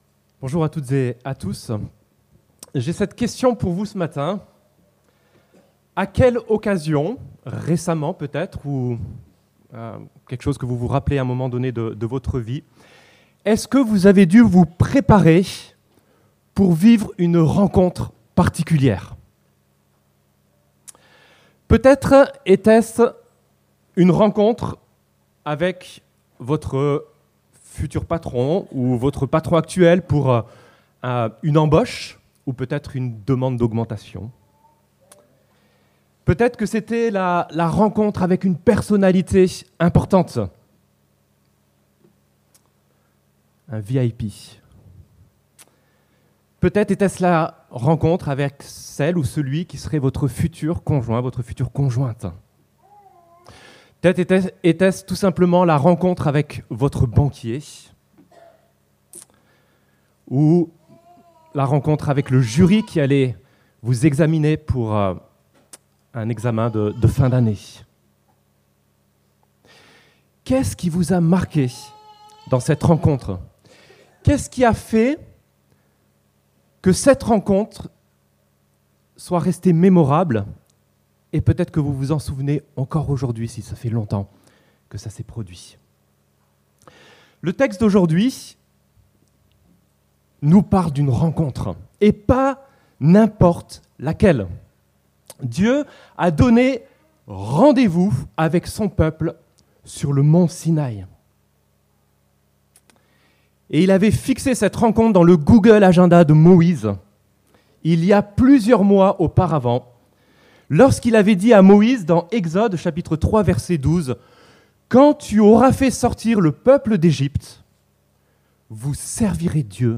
Quand Dieu rencontre son peuple - Prédication de l'Eglise Protestante Evangélique de Crest sur le livre de l'Exode